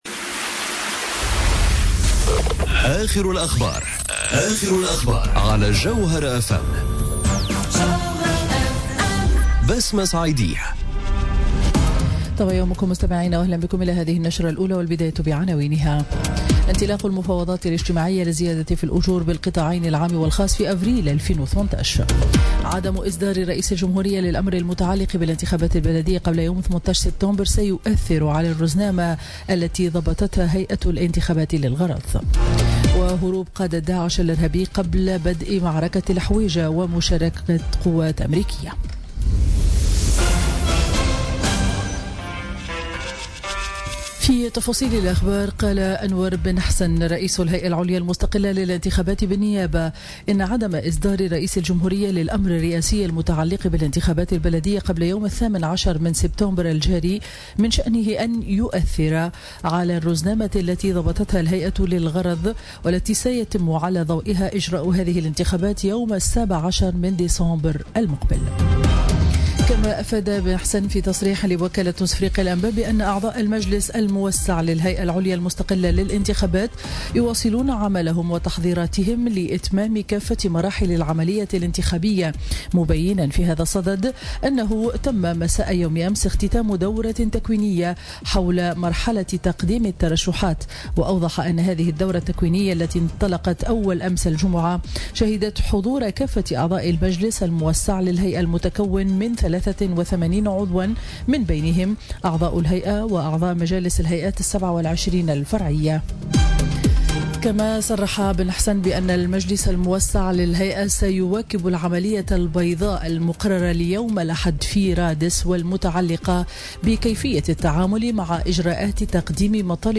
نشرة أخبار السابعة صباحا ليوم الاحد 10 سبتمبر 2017